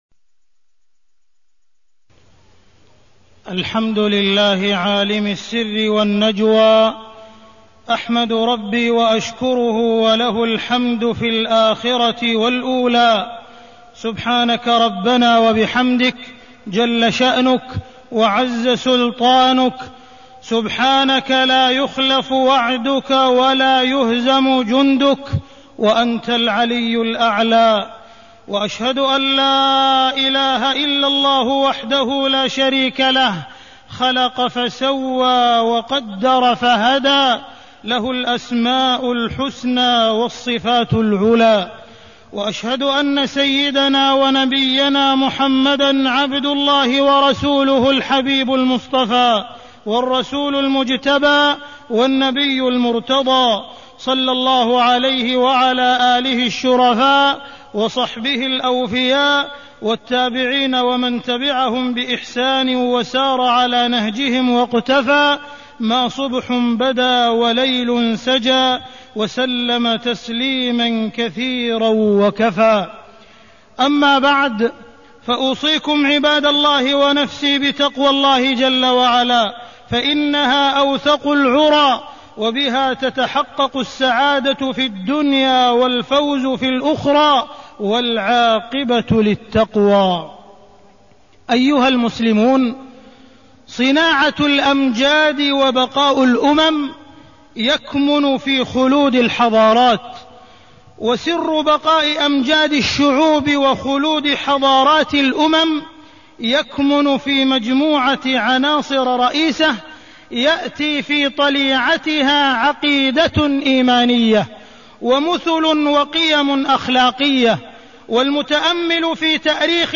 تاريخ النشر ١٨ ذو القعدة ١٤٢٢ هـ المكان: المسجد الحرام الشيخ: معالي الشيخ أ.د. عبدالرحمن بن عبدالعزيز السديس معالي الشيخ أ.د. عبدالرحمن بن عبدالعزيز السديس الحضارة الإسلامية